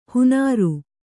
♪ hunāru